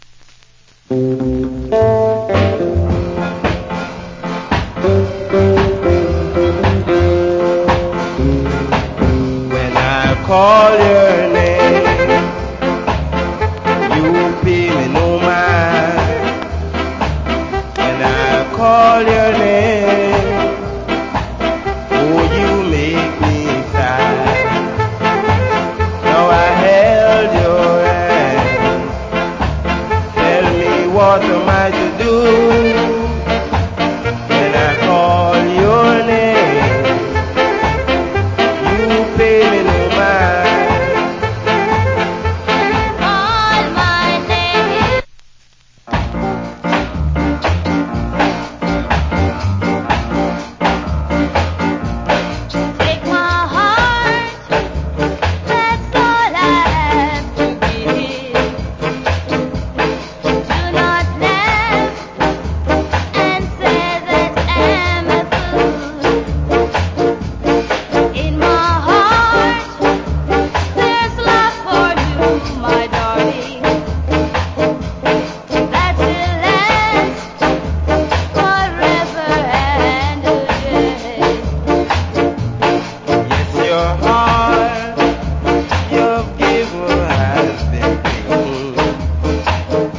Great Duet Ska Vocal.